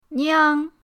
niang1.mp3